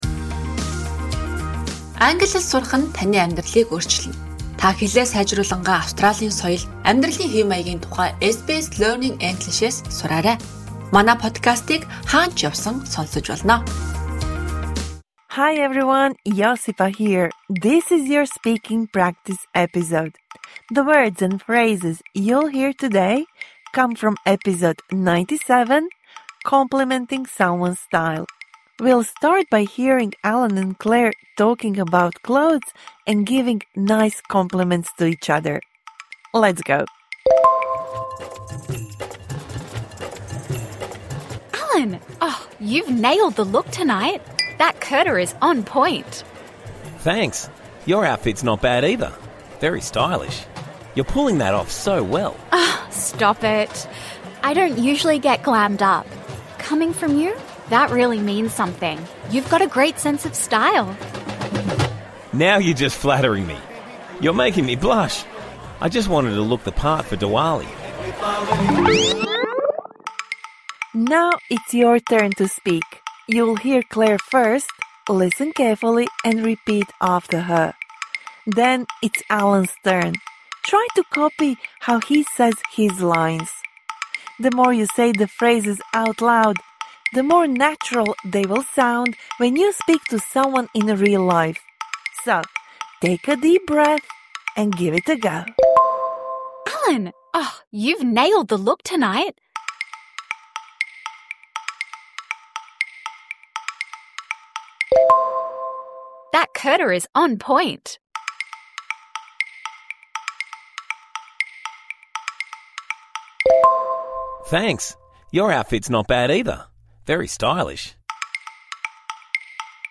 This bonus episode provides interactive speaking practice for the words and phrases you learnt in #97 Complimenting someone’s style (Med).
voiced the characters of Allan and Claire